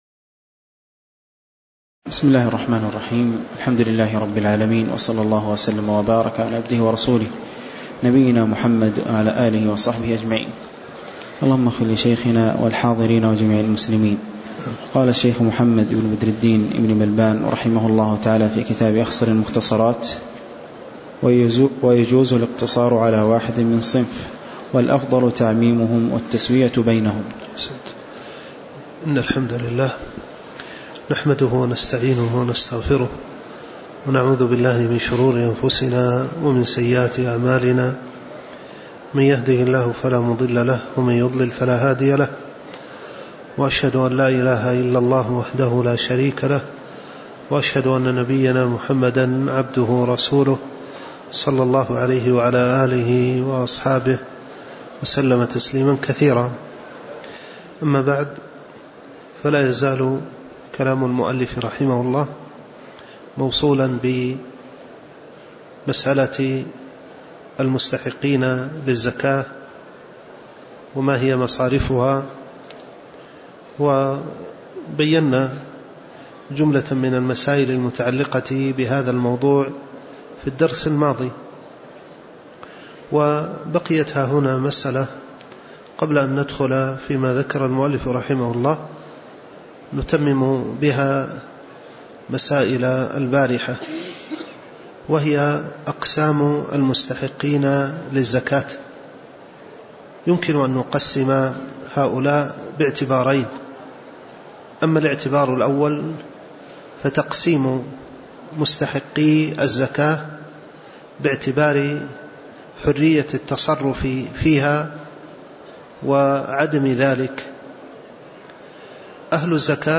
تاريخ النشر ١ صفر ١٤٤٠ هـ المكان: المسجد النبوي الشيخ